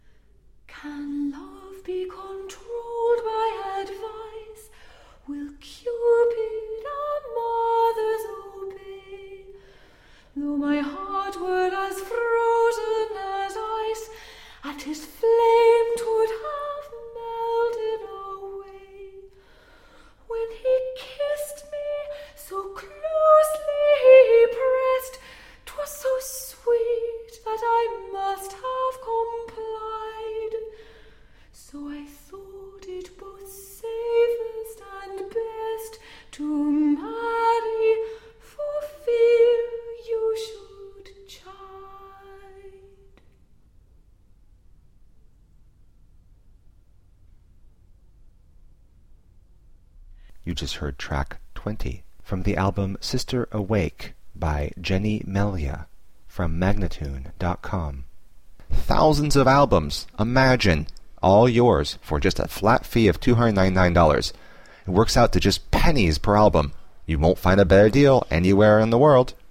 Elizabethan lute and folk song.
Classical, Renaissance, Classical Singing
Lute